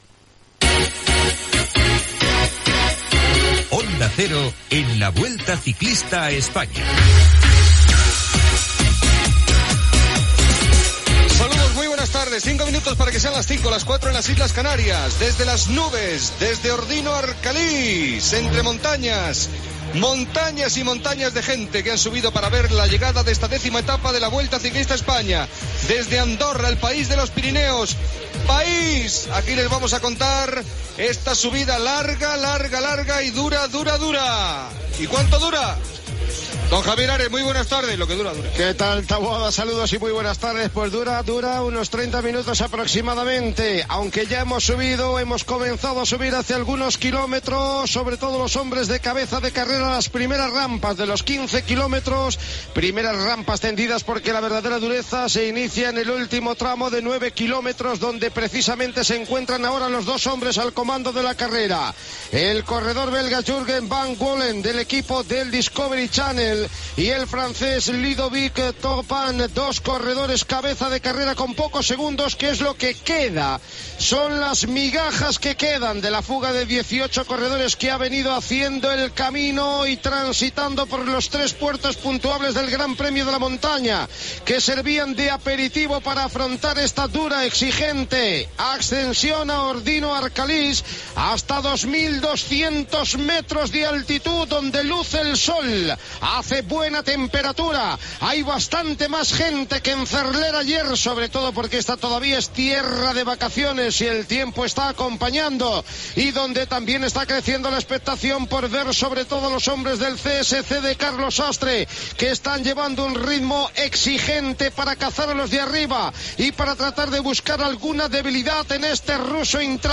Transmissió del final de la 10ena etapa de la Vuelta Ciclista a España de l'any 2007, des d'Ordino-Arcalís al Principat d'Andorra Gènere radiofònic Esportiu